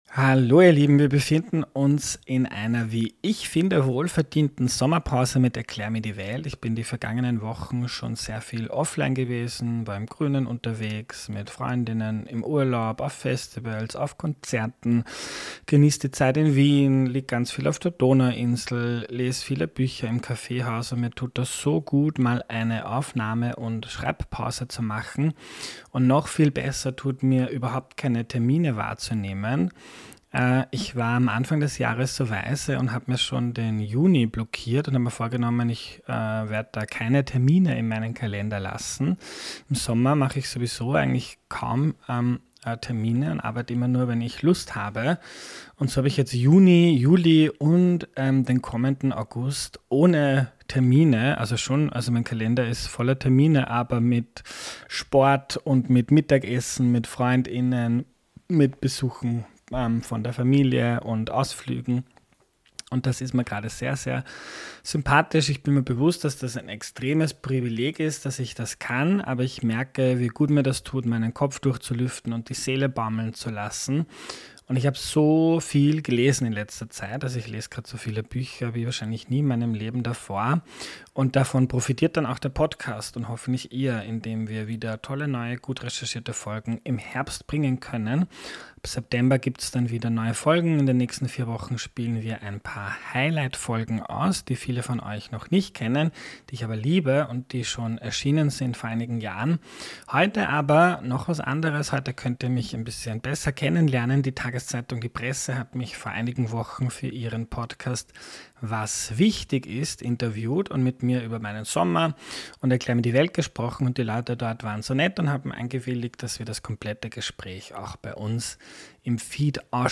Die Presse hat mit mir in ihrem Podcast "Was wichtig ist" über meinen Sommer gesprochen.